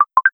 beepbadeep.wav